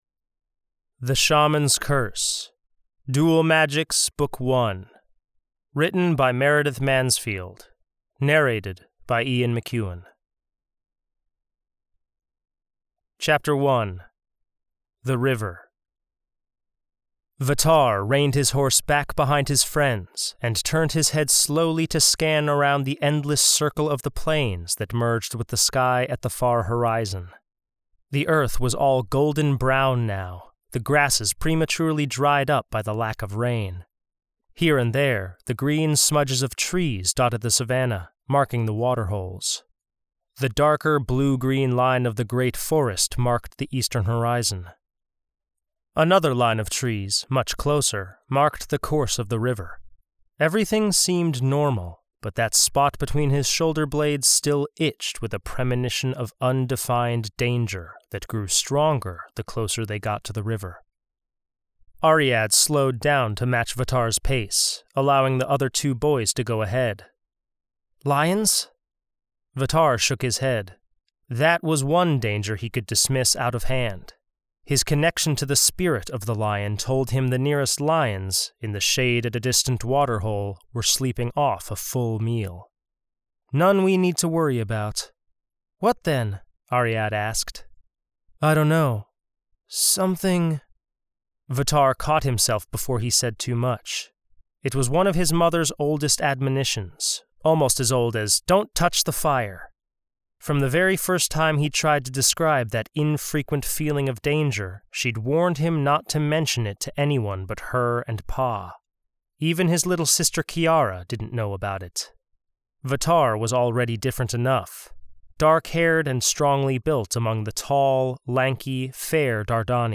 It’s something I’ve really needed to do for a while, especially since I now have an audiobook version of THE SHAMAN’S CURSE.